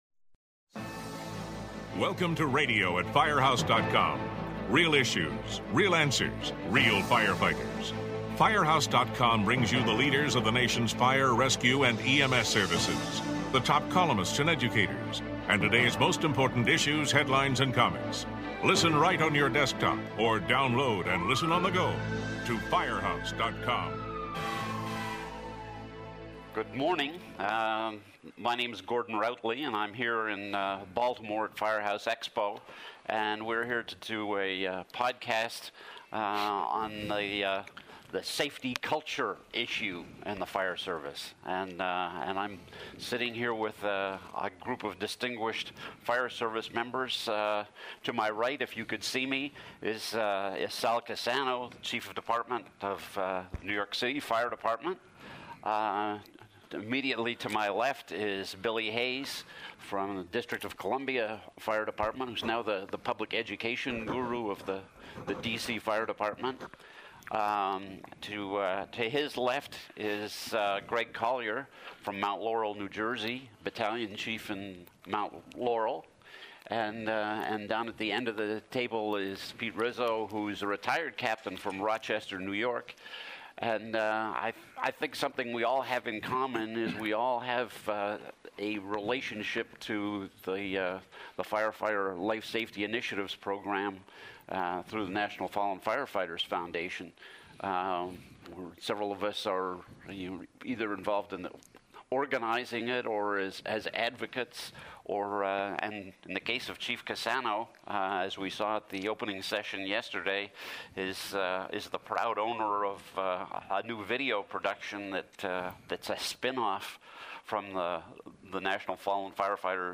The panel focuses on the building blocks needed to create a safer work environment, both on the street and in the firehouse. Each guests talks about the challenges in their region and offers advice on how to overcome them.
This podcast was recorded at Firehouse Expo in July.